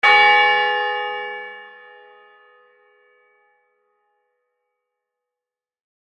church_bell.mp3